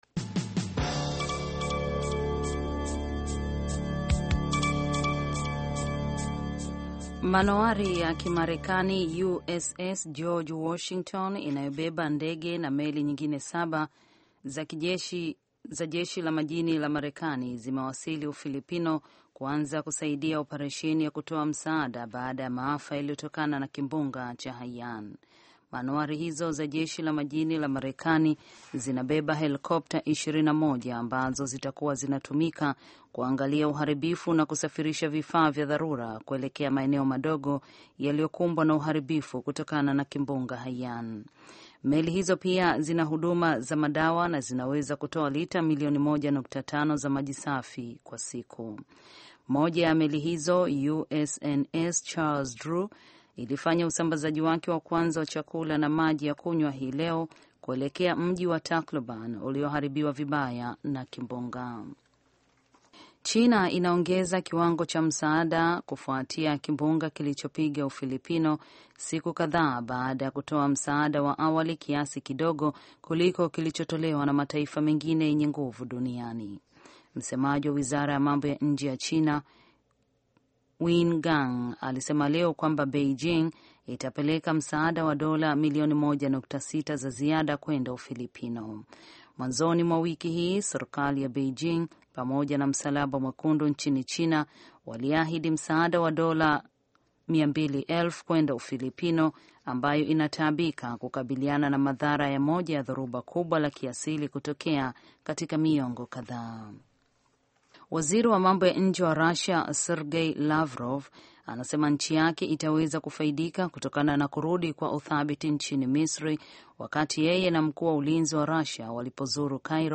Taarifa ya Habari VOA Swahili - 6:20